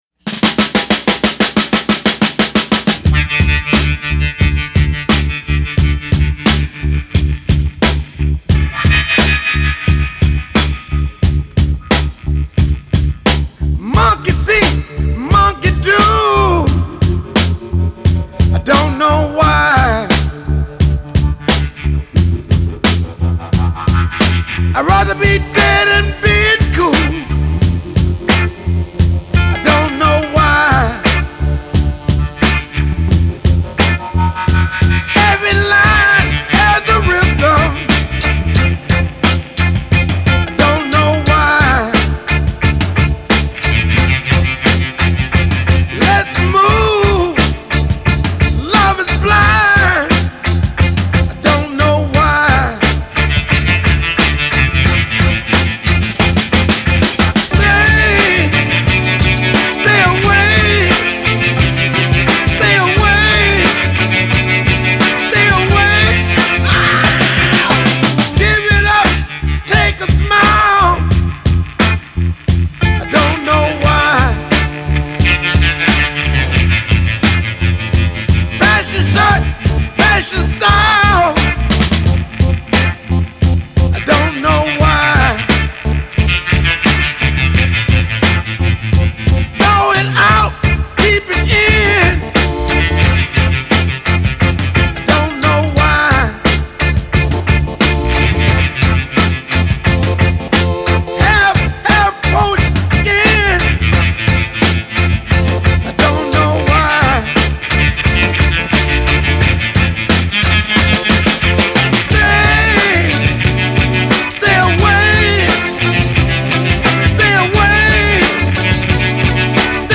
I really enjoyed the James Brown-esque interpretation.